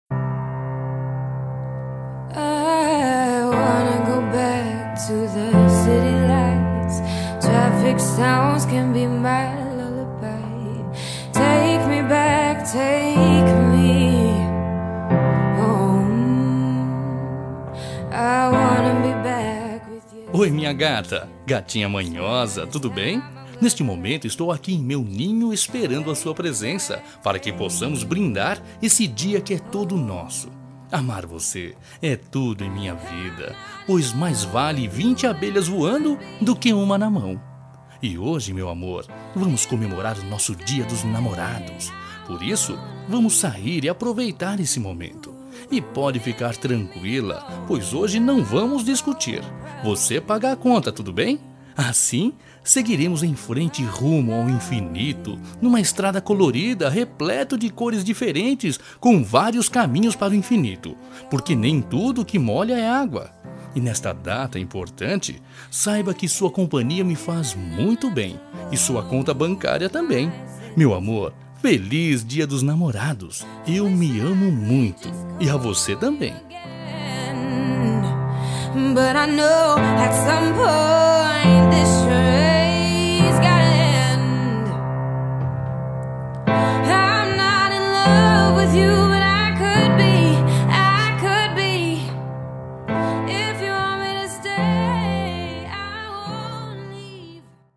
Gozação-Romântica
Voz Masculina